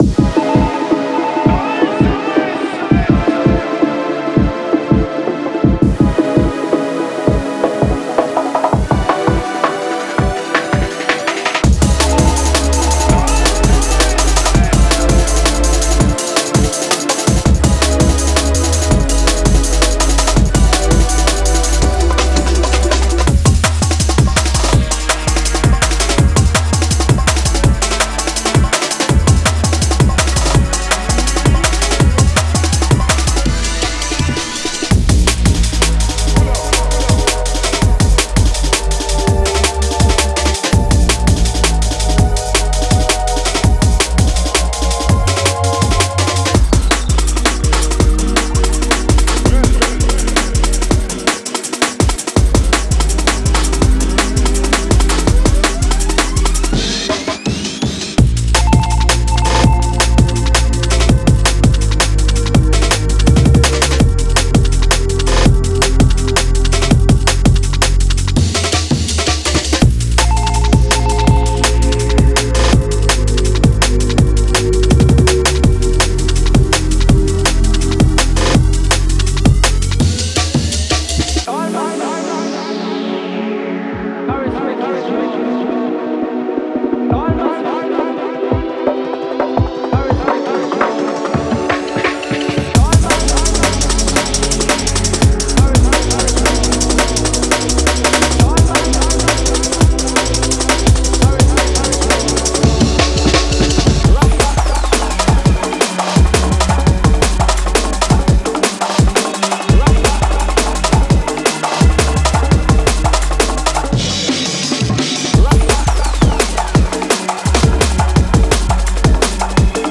Genre:Jungle
165〜170BPMのサンプルを収録した
デモサウンドはコチラ↓